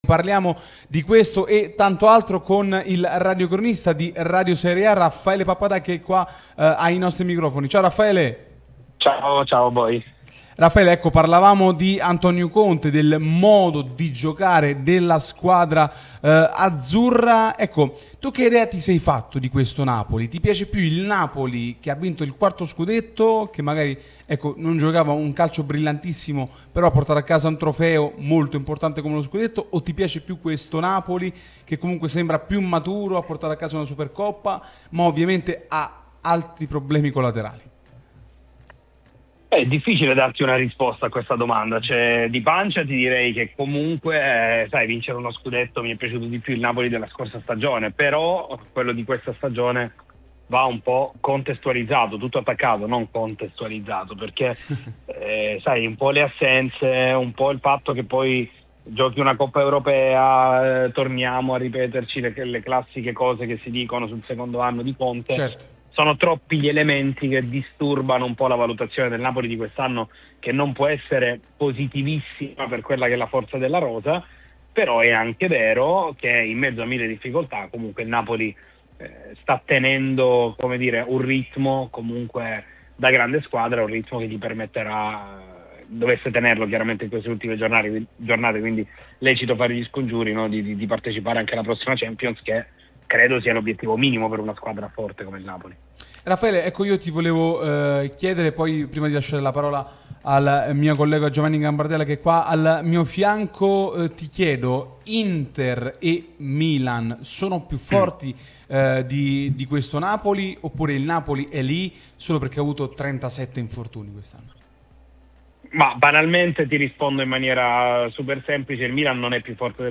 trasmissione sulla nostra Radio Tutto Napoli